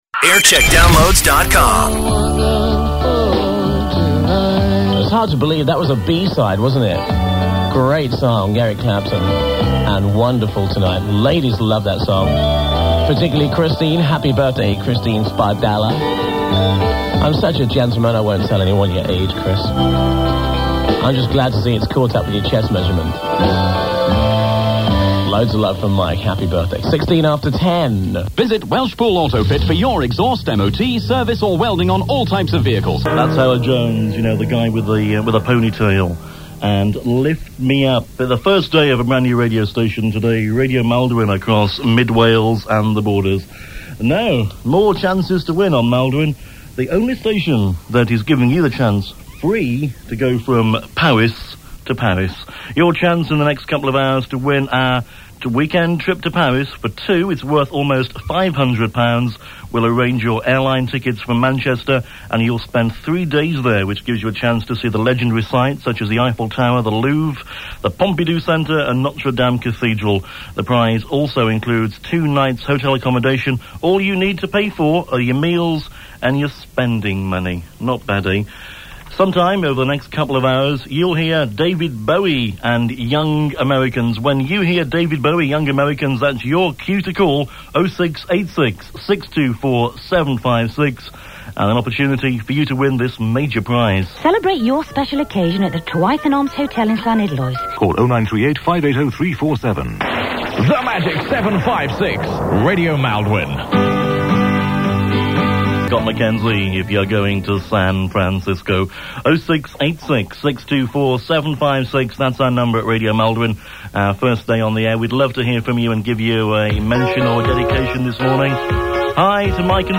Jingle Montage